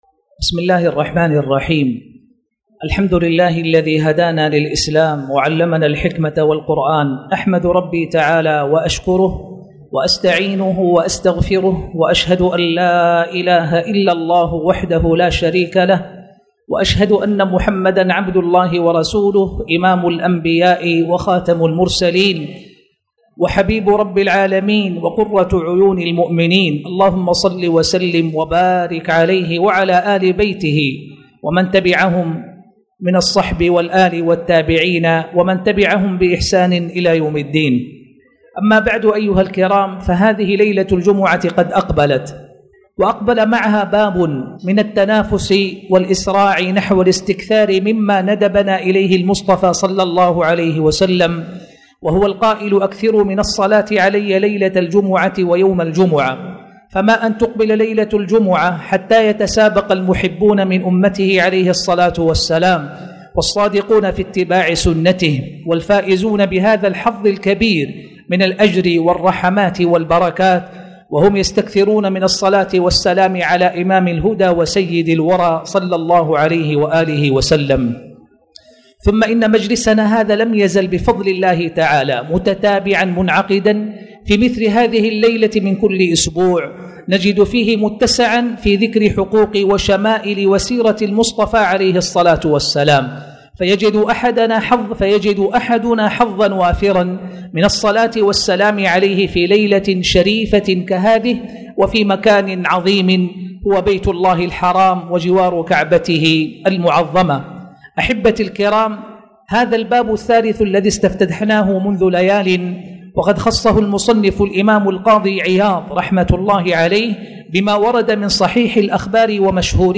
تاريخ النشر ٢١ ربيع الثاني ١٤٣٨ هـ المكان: المسجد الحرام الشيخ